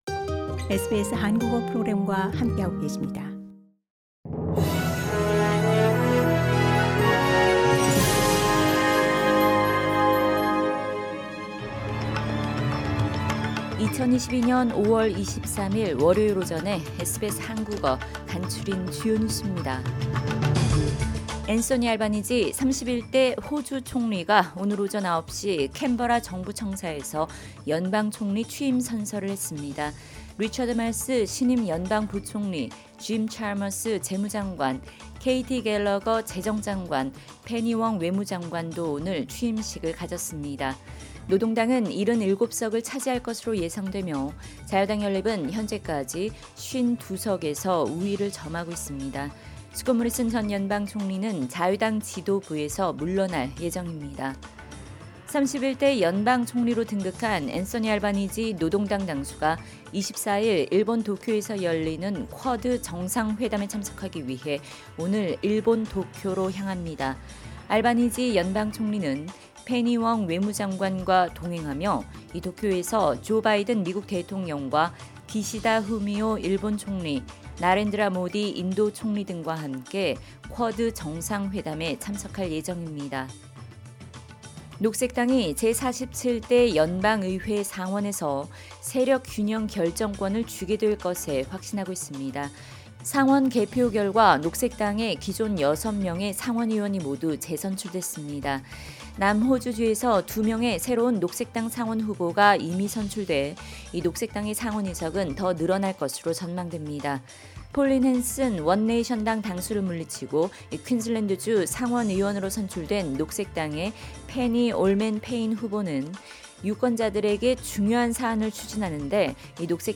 SBS 한국어 아침 뉴스: 2022년 5월 23일 월요일
2022년 5월 23일 월요일 아침 SBS 한국어 간추린 주요 뉴스입니다.